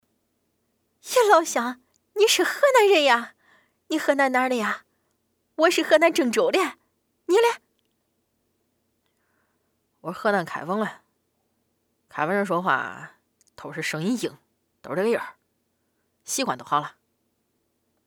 电影对白